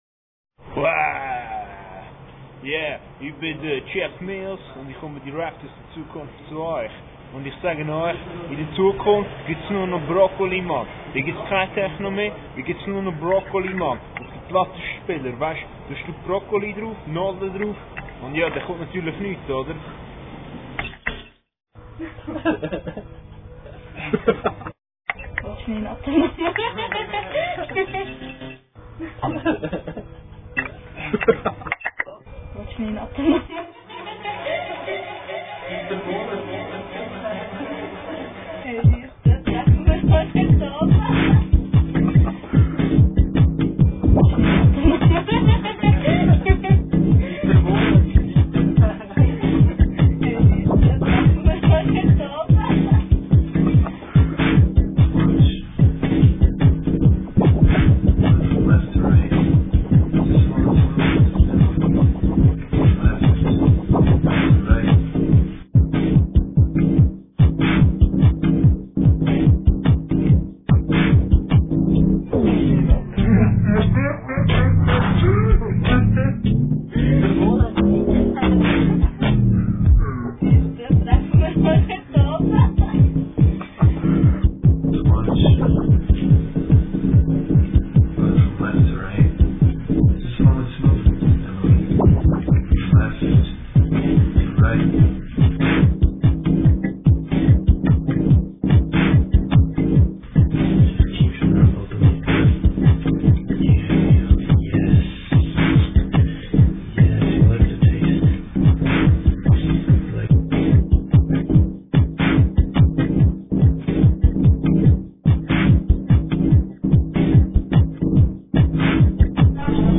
features samples recorded in the first half of the 00ies